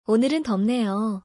Pronunciation : o-neu-reun deop-ne-yo